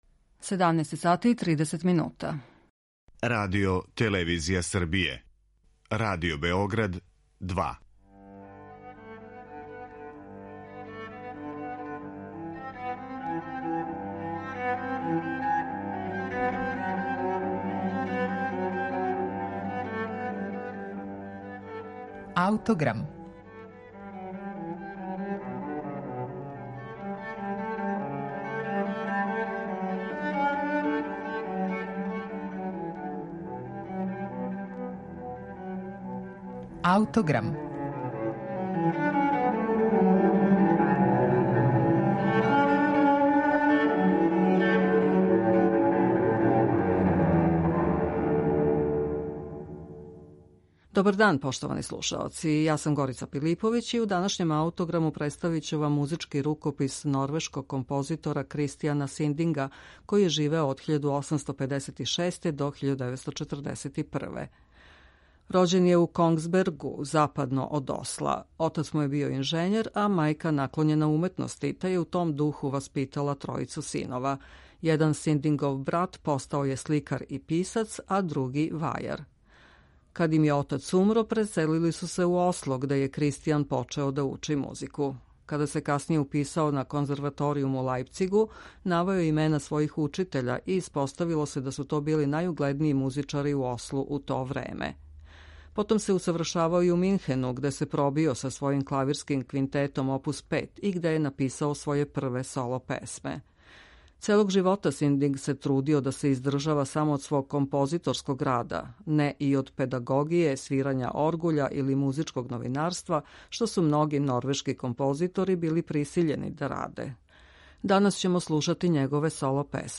представља његове соло-песме